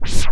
WIND SCRAT.3.wav